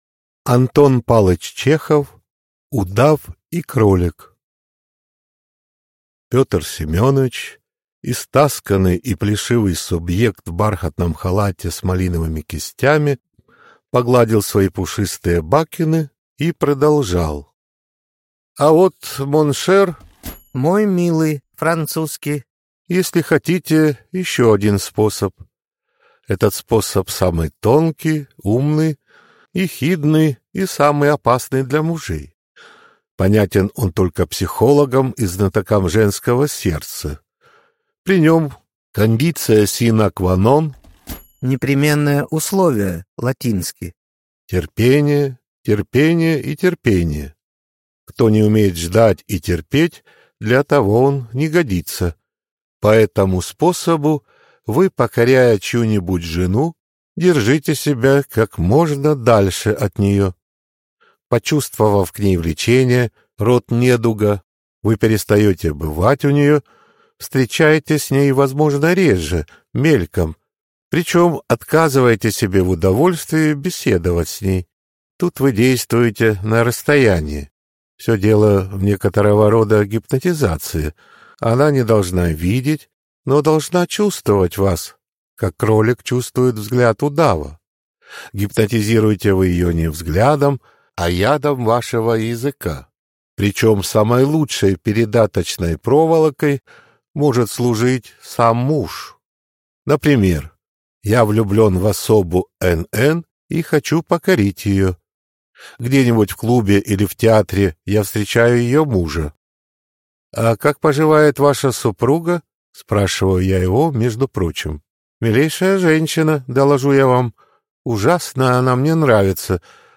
Аудиокнига Удав и кролик | Библиотека аудиокниг